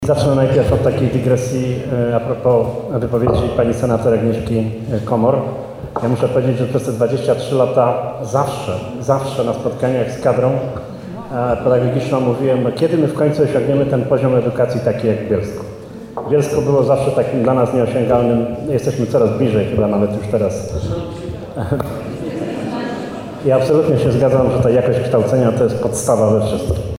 Blisko 3 godziny trwała debata jaką zorganizowano w magistracie w Bielsku-Białej.